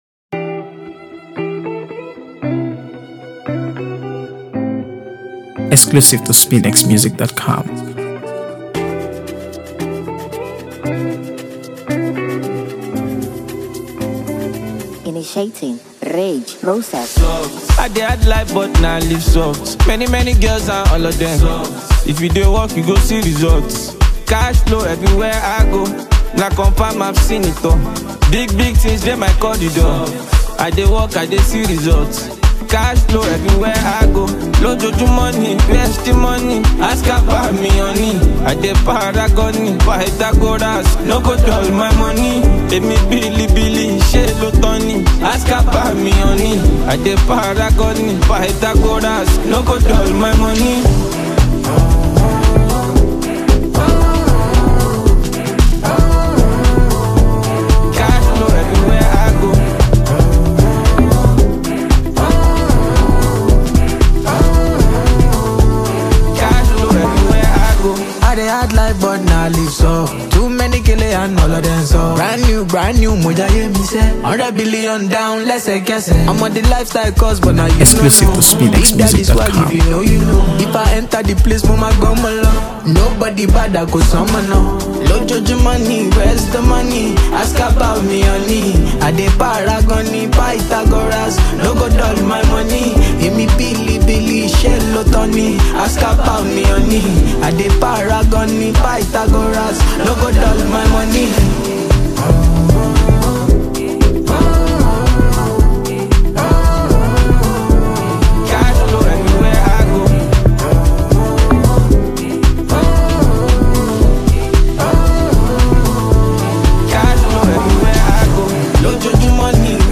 AfrobeatsTrending Music